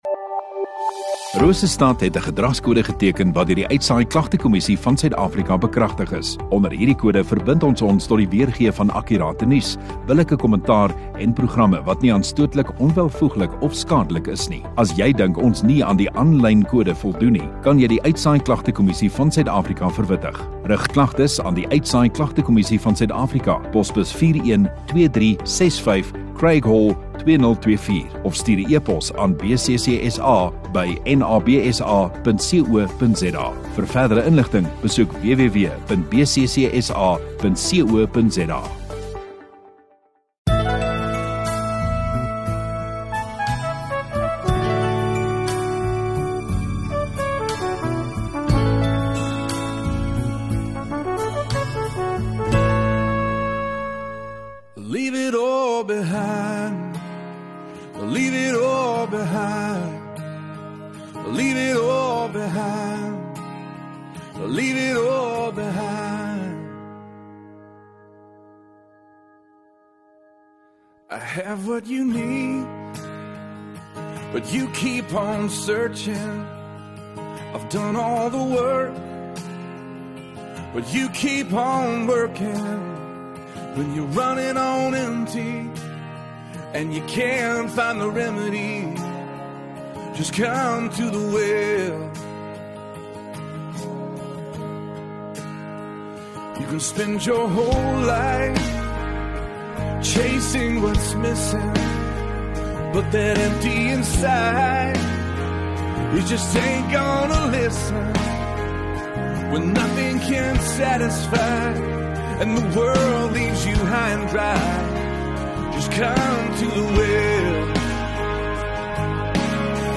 18 May Sondagoggend Erediens